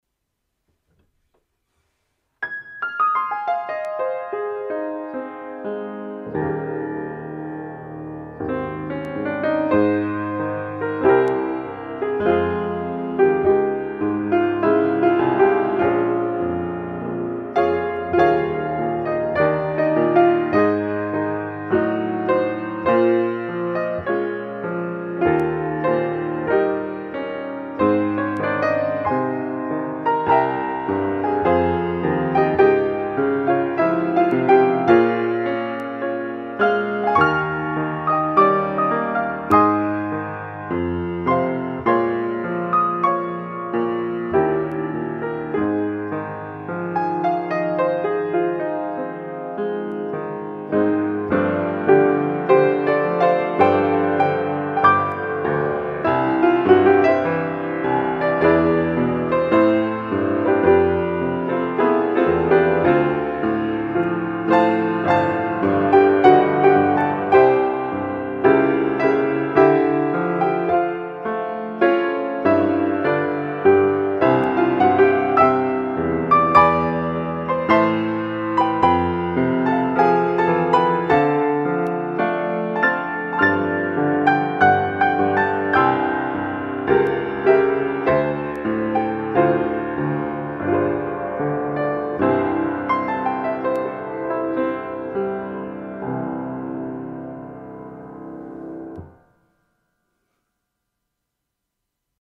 Violin + Piano